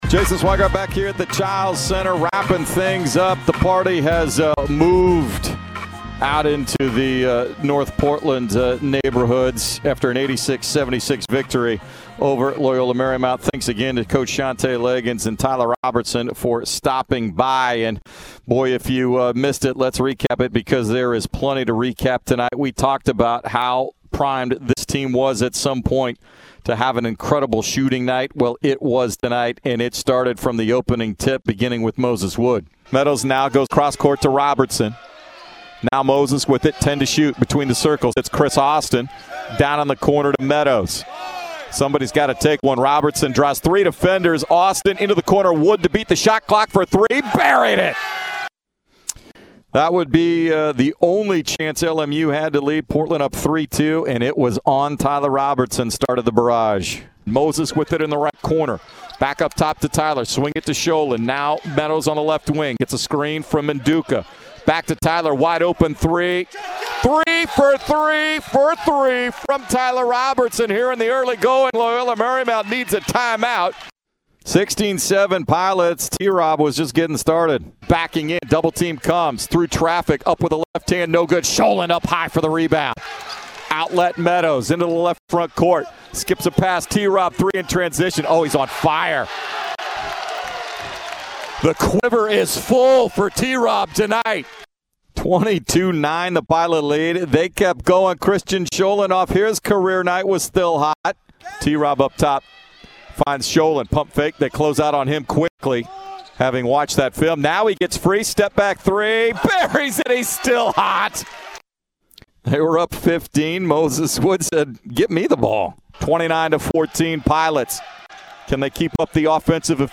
Radio Highlights vs. LMU